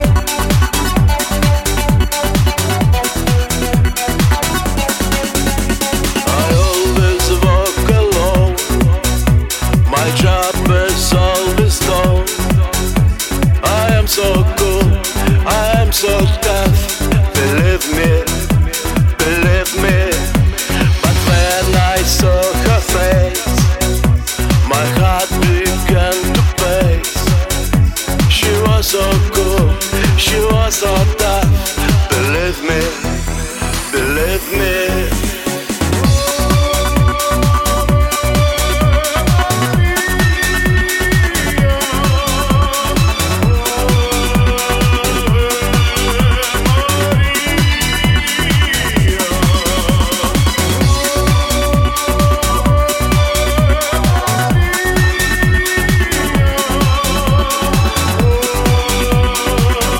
Pop
Лучшие танцевальные треки наступающего сезона!